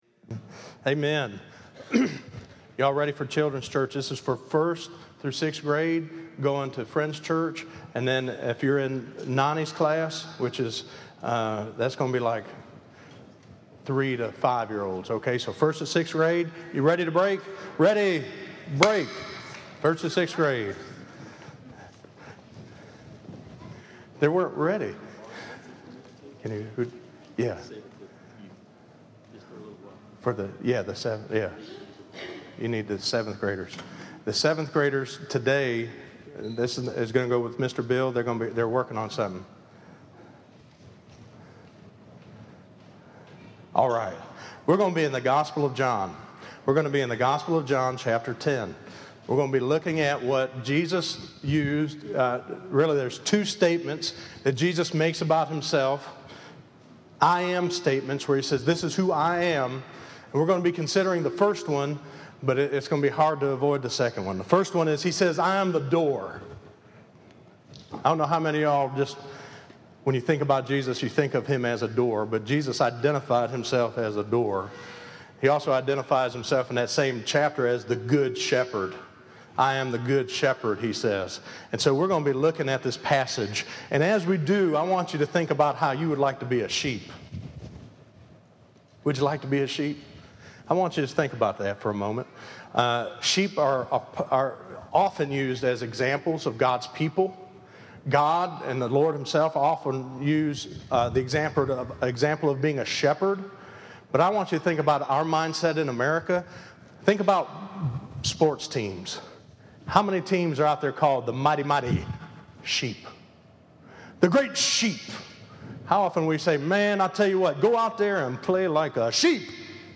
Listen to Jesus in the Door and He let me in - 08_09_15_sermon.mp3